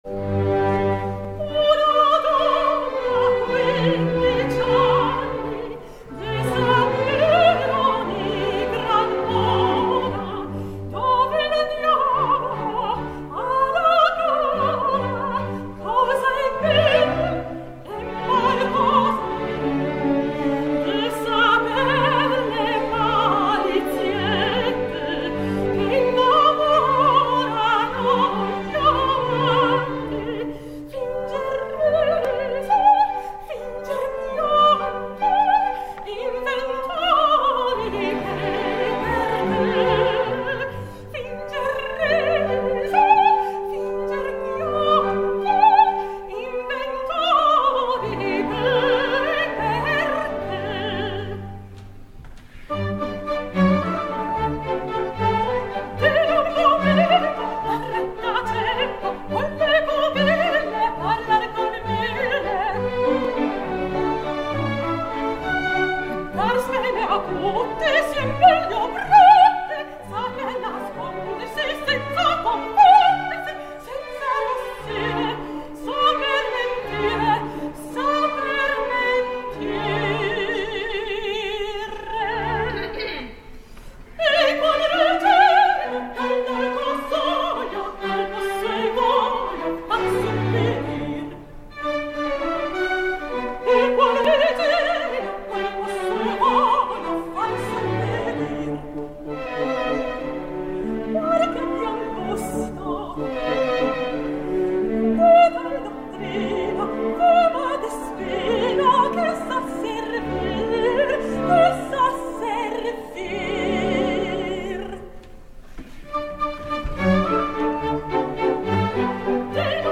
LA Opera, 18 de setembre de 2011.